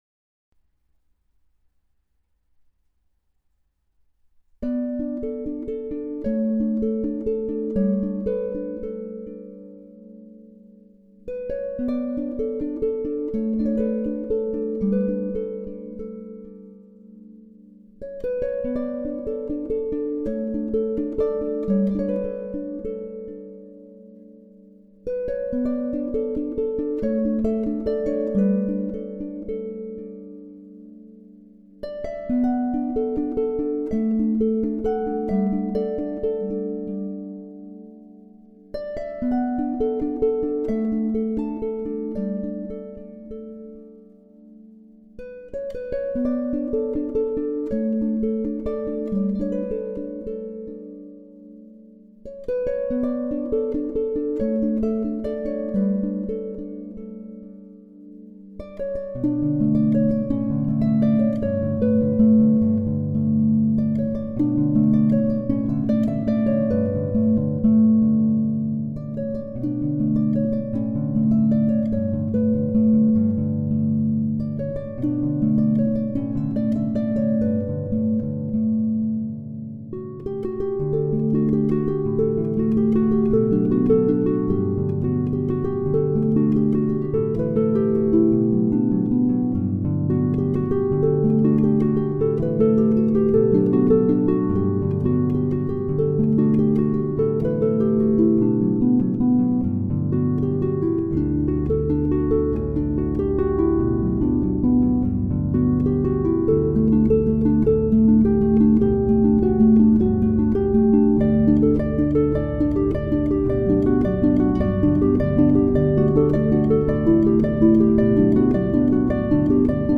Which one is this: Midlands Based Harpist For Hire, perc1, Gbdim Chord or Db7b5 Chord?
Midlands Based Harpist For Hire